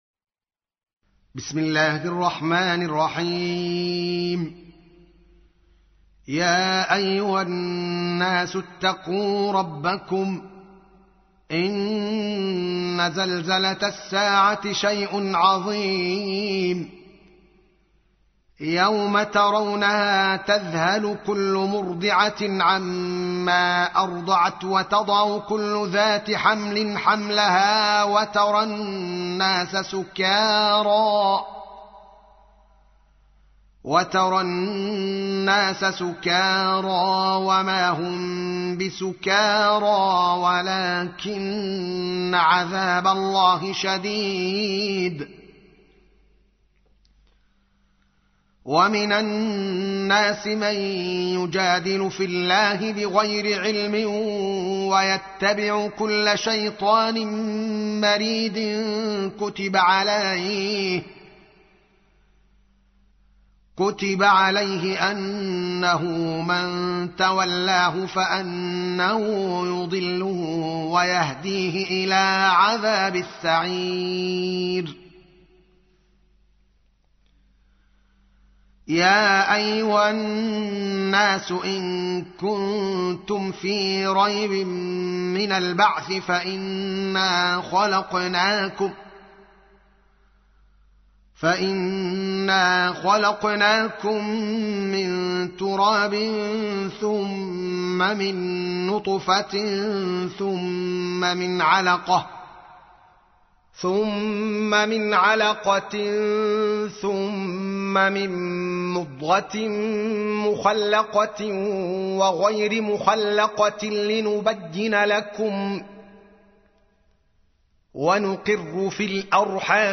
تحميل : 22. سورة الحج / القارئ الدوكالي محمد العالم / القرآن الكريم / موقع يا حسين